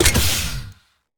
laser-turret-deactivate-03.ogg